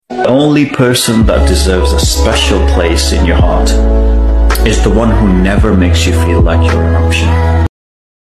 Best Motivational Speech. Life Lesson, Must Watch.